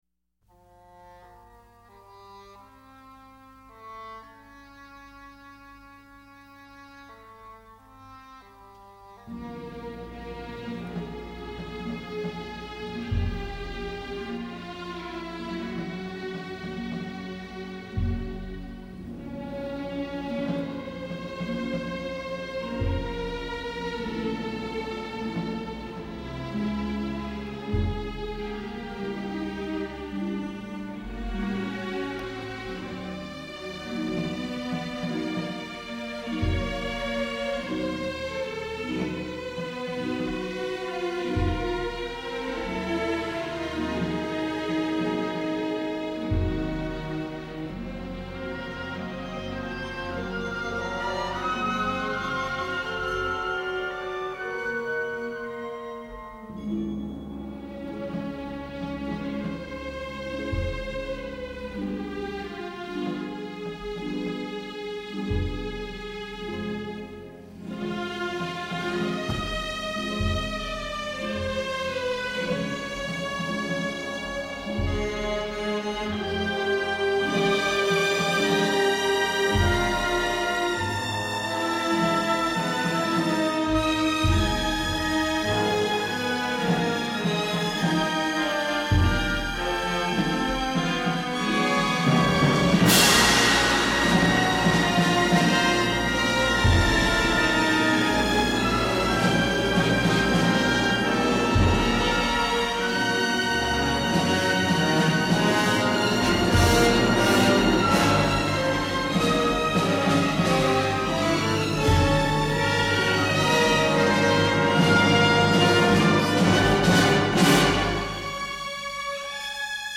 soprano spinto